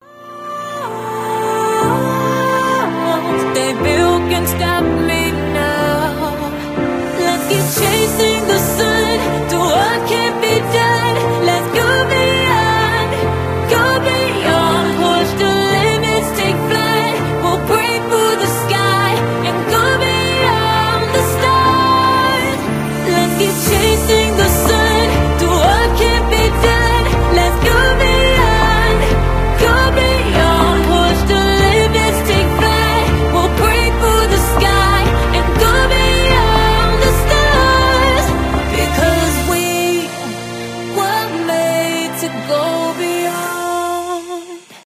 женский голос , вдохновляющие